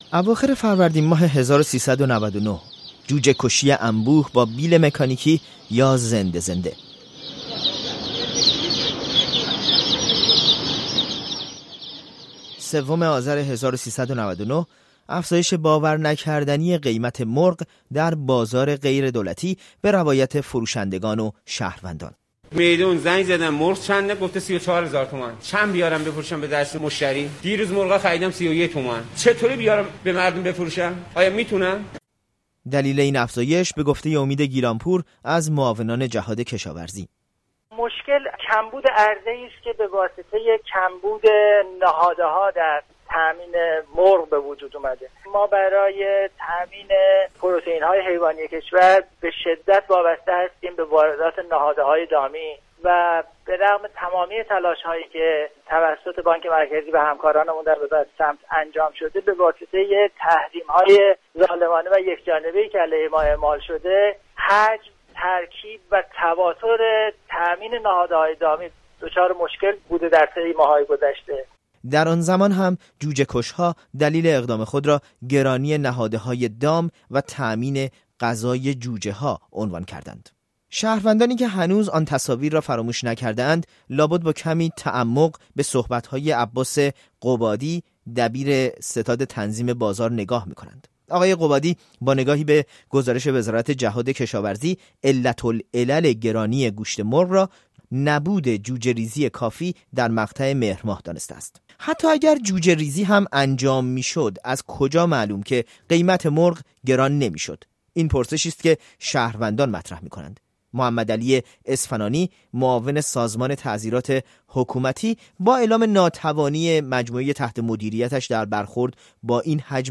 وعده های مقام های دولتی برای کاهش قیمت گوشت مرغ در روزهای آینده همچنان ادامه دارد. کمبود نهاده های دامی، جوجه ریزی، عرضه نامناسب و تحریم، کدام کلید واژه گرانی گوشت مرغ است؟ گزارشی